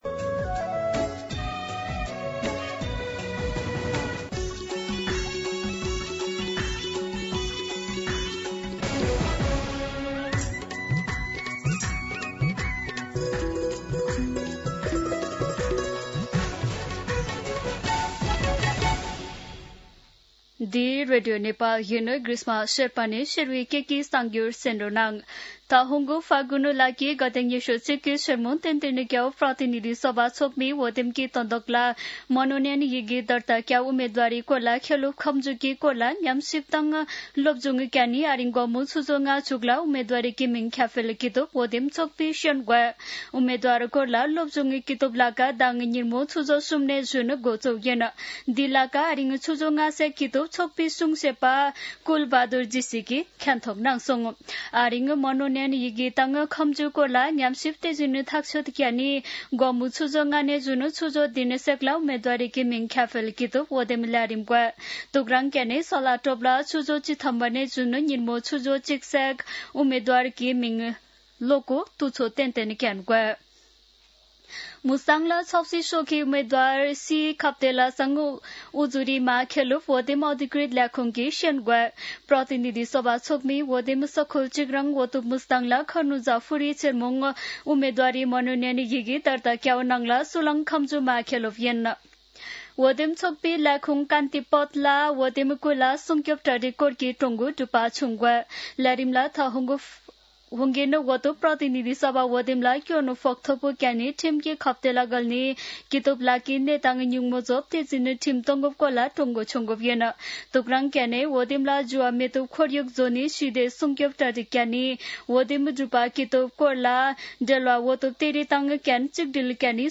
शेर्पा भाषाको समाचार : ८ माघ , २०८२
Sherpa-News-10-8.mp3